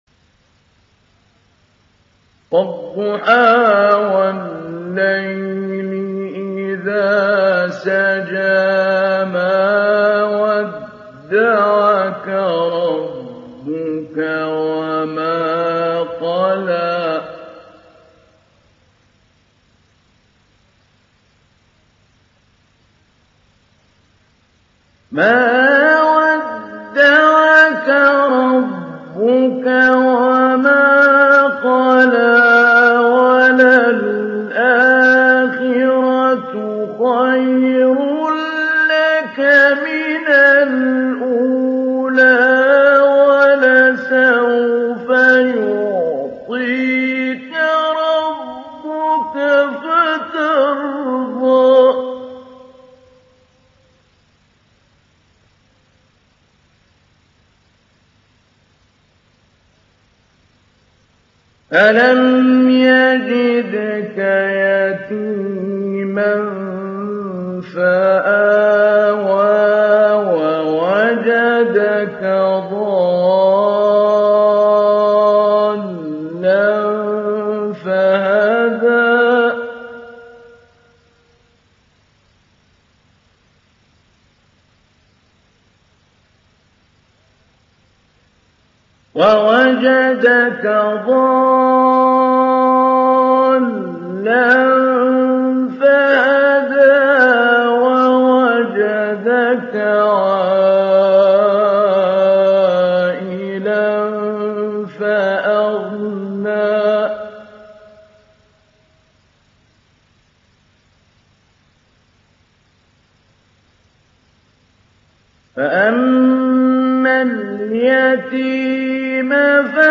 دانلود سوره الضحى mp3 محمود علي البنا مجود روایت حفص از عاصم, قرآن را دانلود کنید و گوش کن mp3 ، لینک مستقیم کامل
دانلود سوره الضحى محمود علي البنا مجود